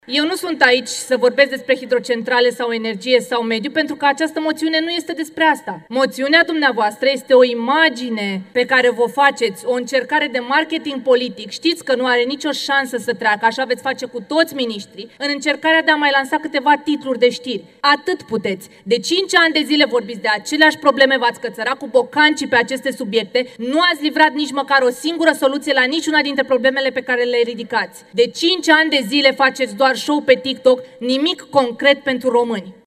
Moțiunea simplă împotriva ministrului Mediului a fost dezbătută în Camera Deputaților.
Diana Buzoianu, ministra Mediului: „De cinci ani de zile faceți doar show pe TikTok, nimic concret pentru români